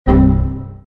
Звуки зависания, сбоя программы
Звук внезапного сбоя в операционной системе Windows (тун)